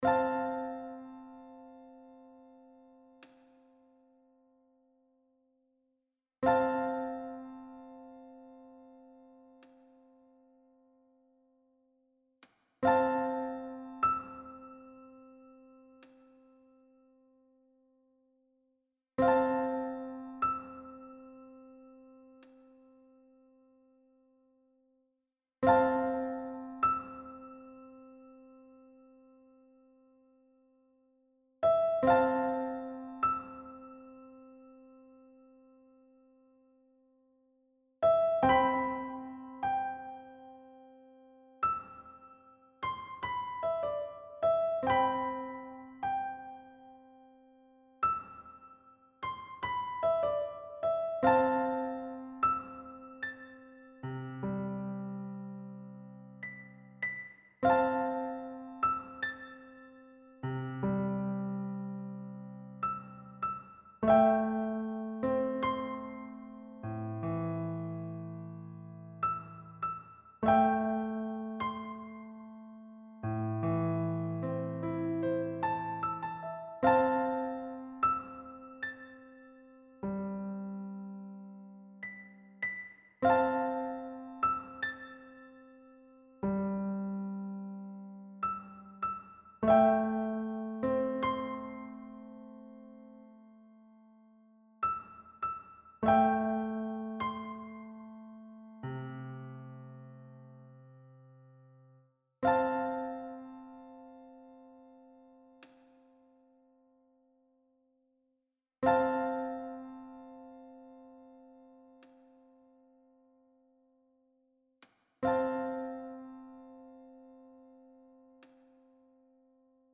【ジャンル】BGM？